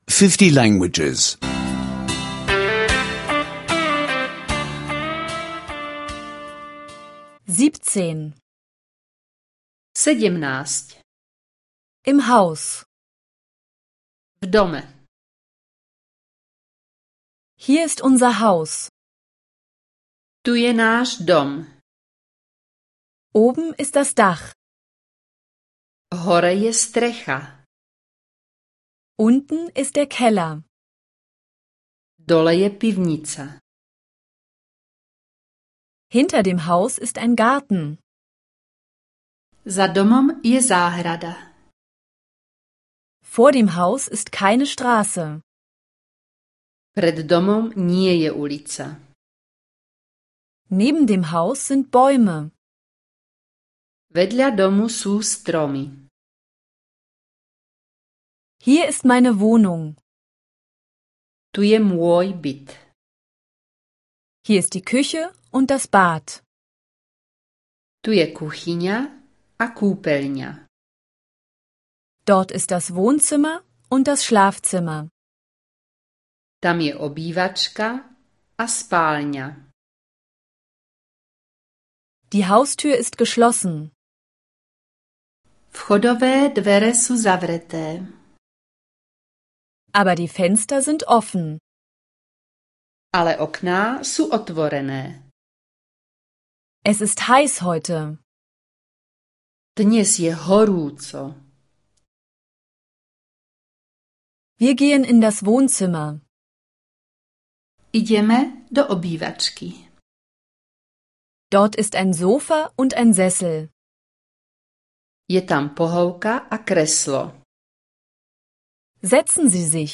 Slowakischer Audio-Lektionen, die Sie kostenlos online anhören können.